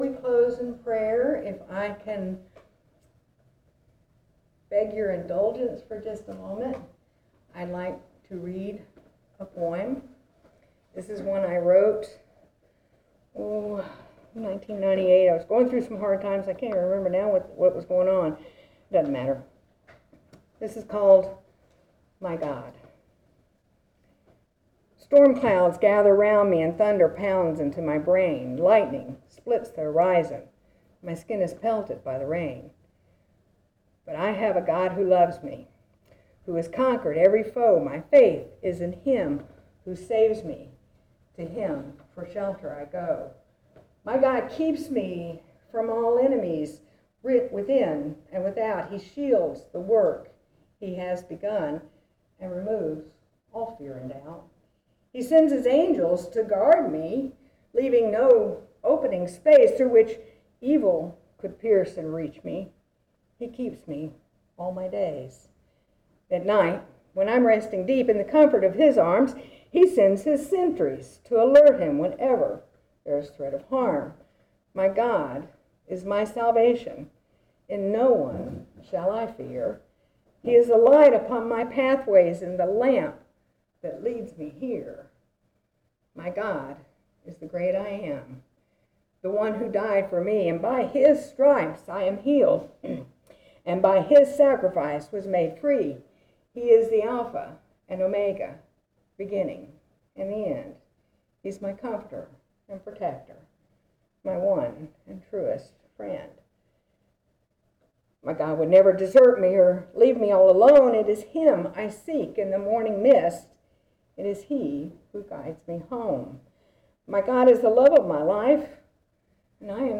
These are the audio recordings and hand-outs for each class.
Reading - My God